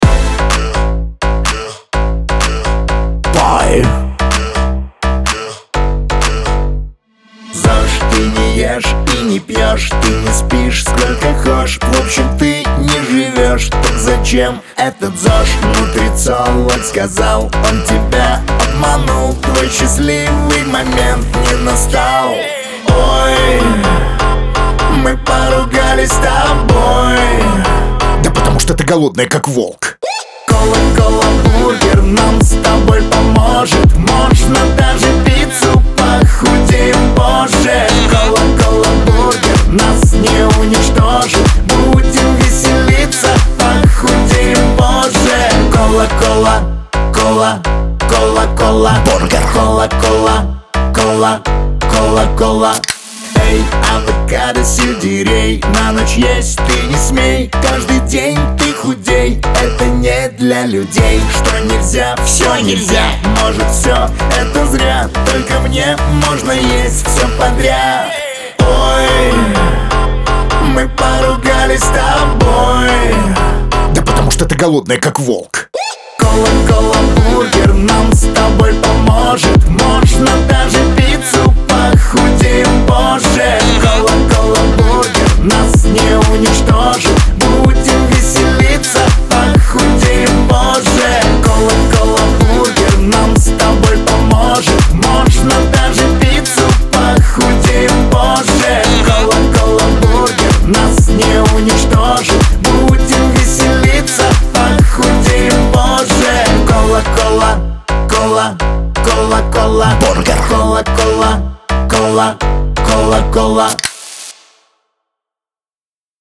Веселая музыка , эстрада , танцевальная музыка , диско